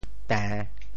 潮州府城POJ tèⁿ 国际音标 [tε̃]